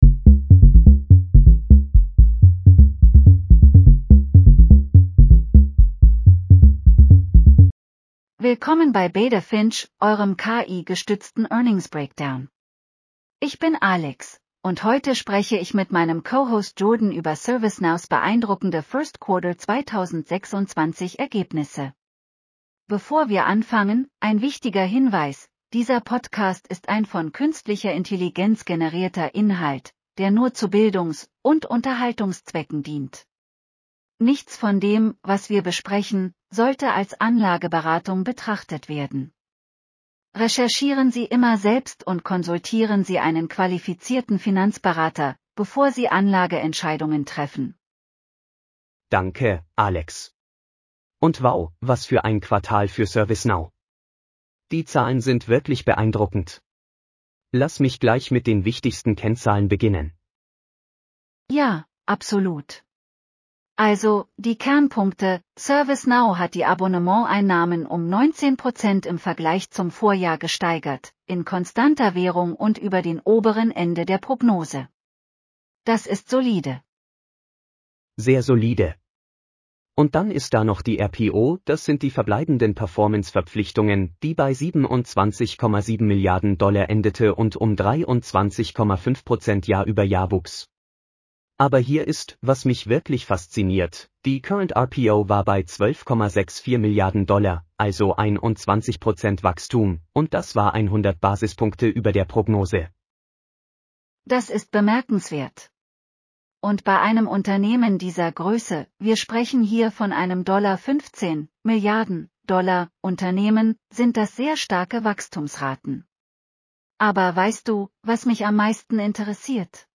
Willkommen bei Beta Finch, eurem KI-gestützten Earnings-Breakdown.